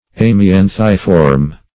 Search Result for " amianthiform" : The Collaborative International Dictionary of English v.0.48: Amianthiform \Am`i*an"thi*form\, a. [Amianthus + -form.] Resembling amianthus in form.
amianthiform.mp3